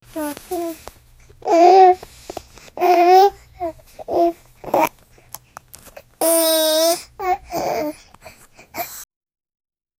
Baby Burps and smiles
baby-burps-and-smiles-6qwgjz7b.wav